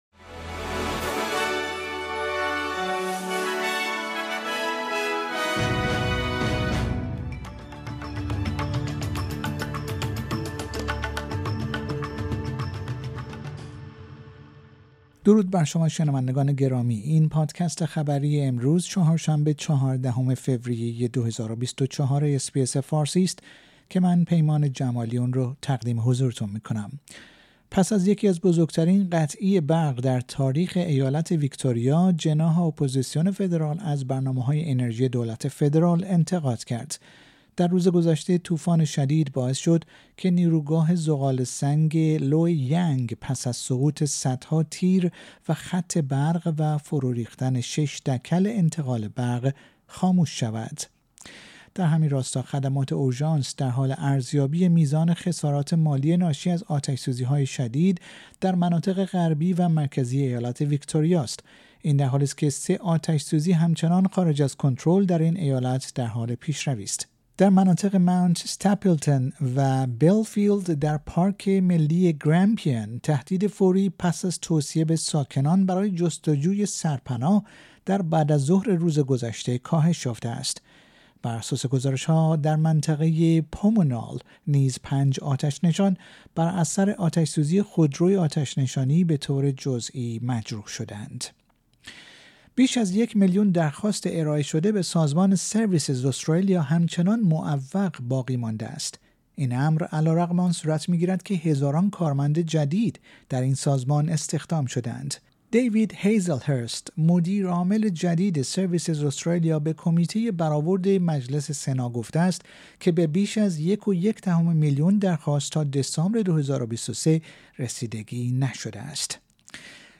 در این پادکست خبری مهمترین اخبار استرالیا و جهان در روز چهارشنبه ۱۴ فوریه ۲۰۲۴ ارائه شده است.